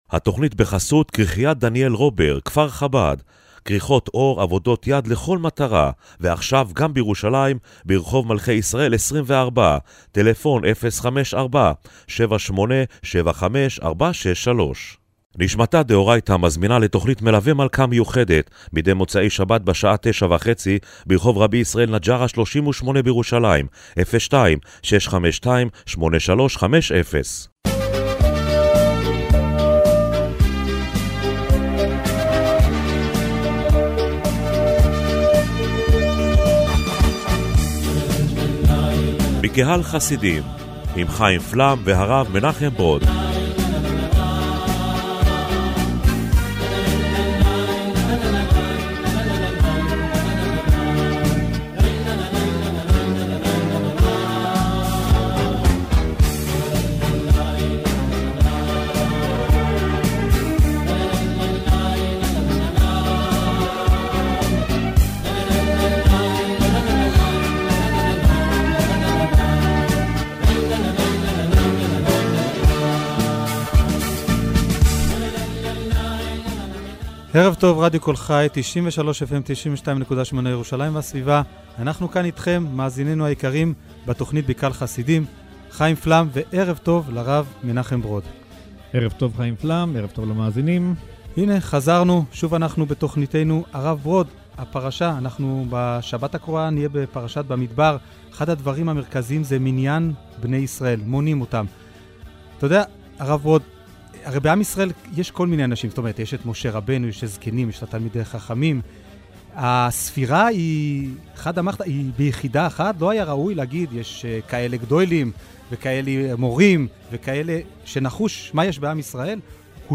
במרכז תכנית הרדיו השבועית בקהל חסידים השבוע עמדה ההתייחסות של החסידות לאנשים פשוטים.